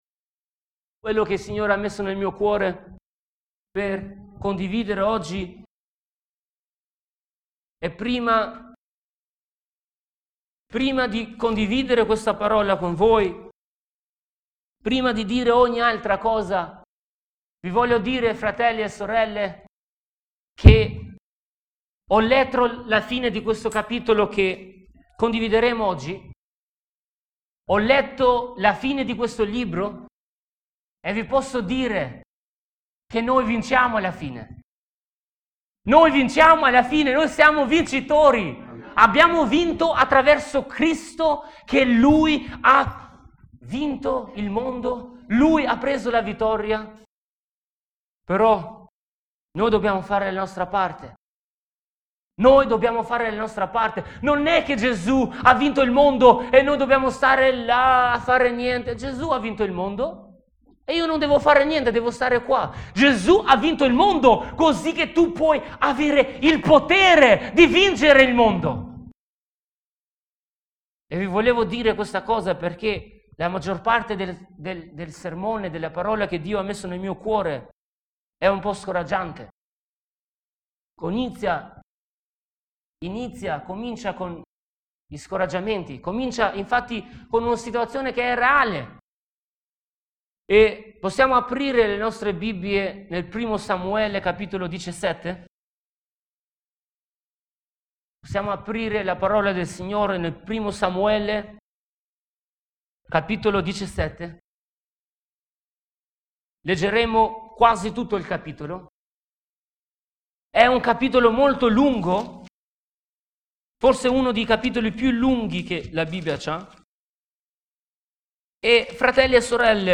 Predicazione 02 dicembre 2012 - La tua fede � sufficiente per affrontare le difficolt� che incontrerai